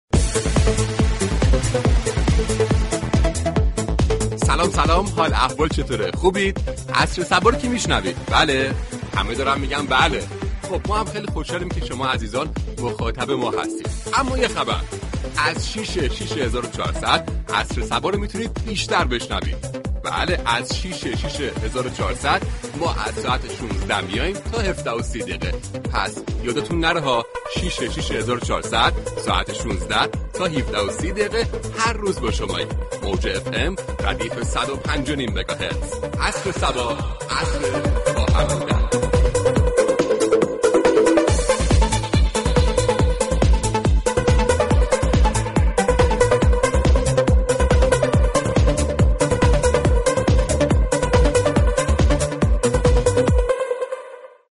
برنامه زنده "عصر صبا " با نگاهی طنز به گرامیداشت روز جهانی غذا می پردازد.
مجله رادیویی عصر صبا با تعامل با مخاطبان و پخش آیتم های طنز به مزایای غذا های سنتی و ایرانی می پردازد.